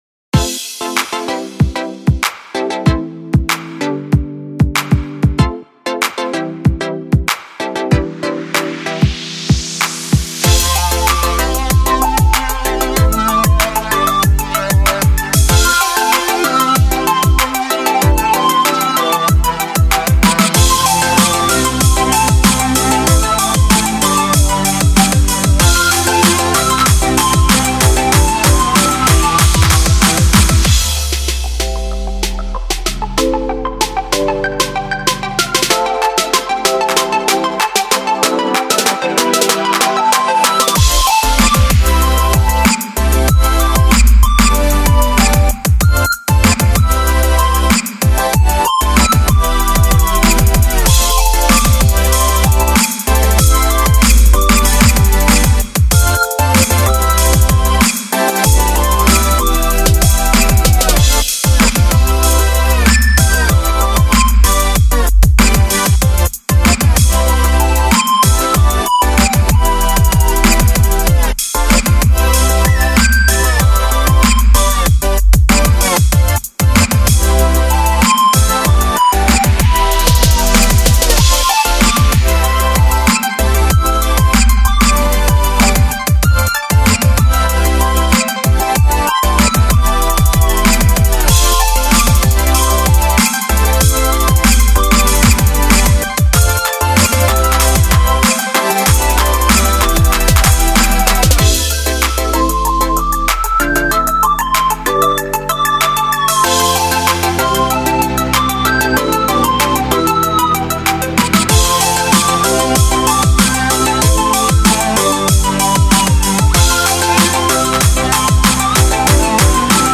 背景音乐为欢快节奏感广告配乐
该BGM音质清晰、流畅，源文件无声音水印干扰